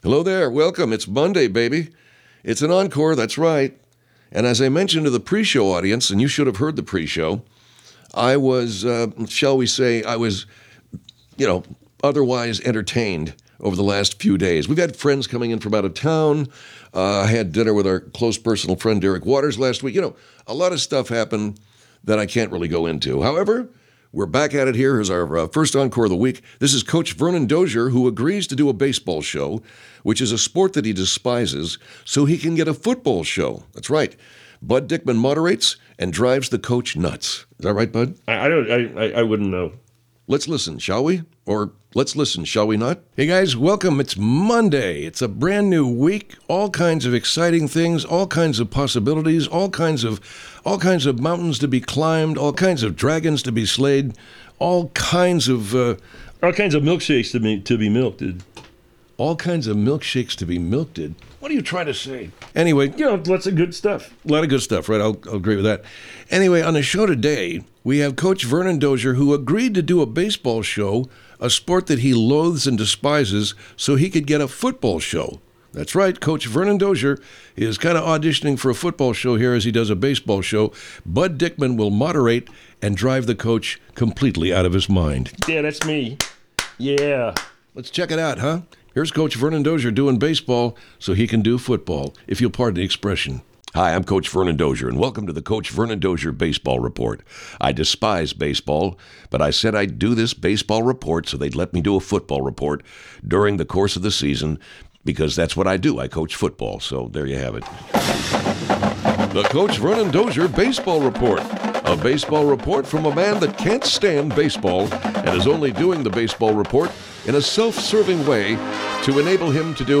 (Spoiler alert: You’ll see what happens when two presenters get the giggles on live radio.)